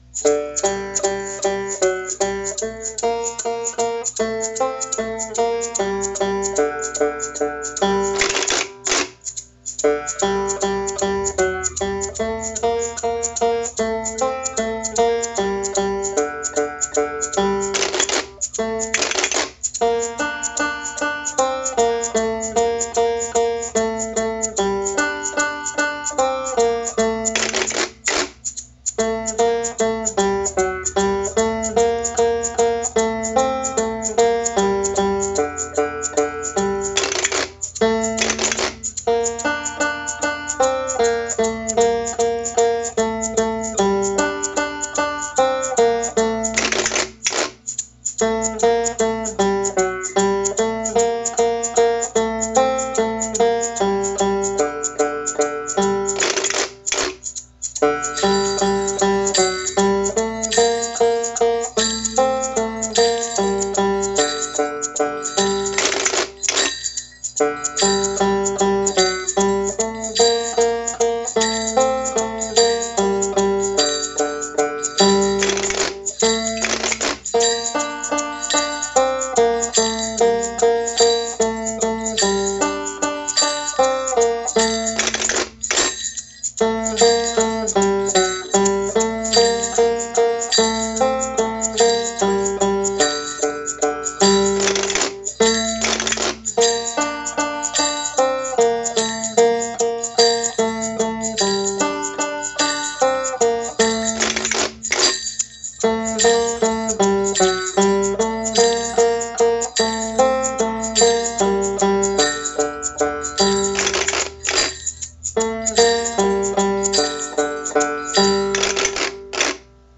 banjo, egg shaker, jingle bells, washboard, and wooden spoons
Music: Welsh traditional, arranged by Dr. Caradog Roberts